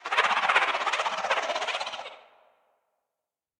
Sfx_creature_arcticray_idle_02.ogg